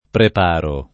preparare v.; preparo [
prep#ro] — costante la pn. piana, sia nell’uso corrente, sia in tutta la tradiz. poet., nonostante qualche sporadica attestaz., tra il ’500 e il ’700, di una pn. sdrucciola [pr$paro] alla latina